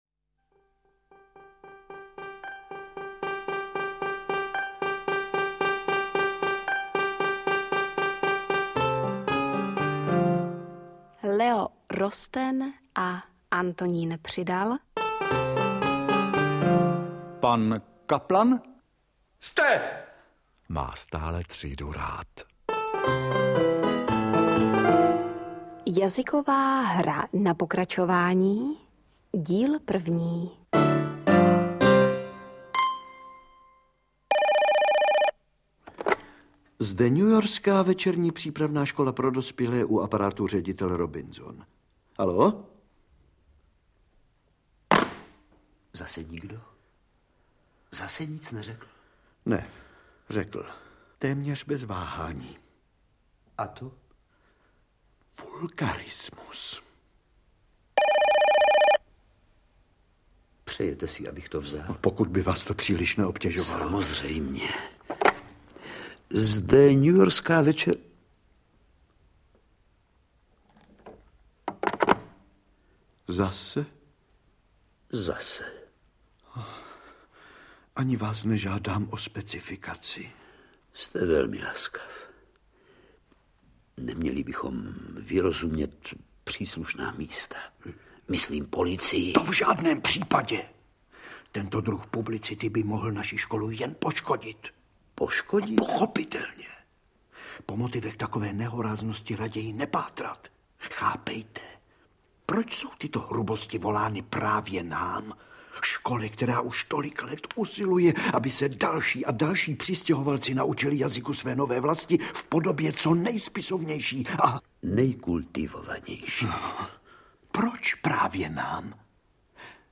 Interpreti:  Miroslav Donutil, Ladislav Lakomý
Sestřih mimořádně úspěšného rozhlasového seriálu z roku 1993 podle knihy L. Rostena o půvabně humorných jazykových a jiných potížích žáků Večerní přípravné školy pro dospělé v New Yorku.